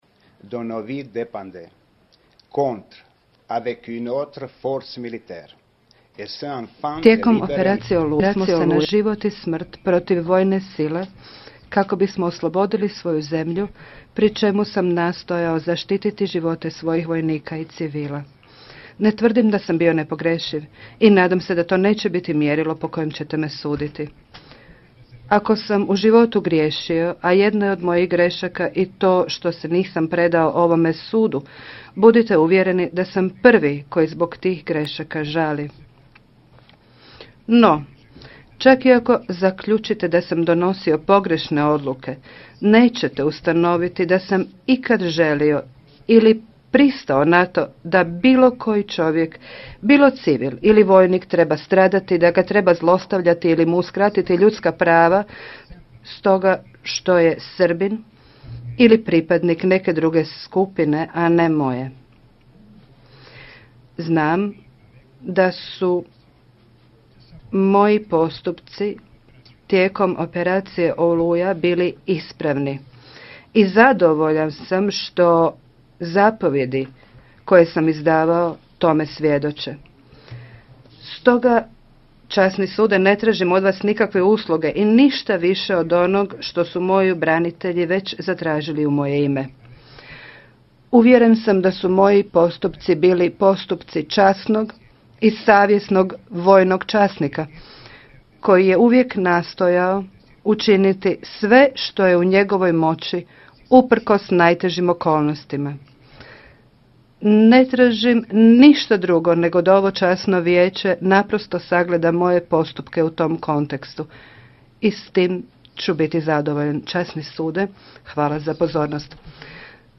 Obraćanje Gotovine i Markača žalbenim sucima na kraju rasprave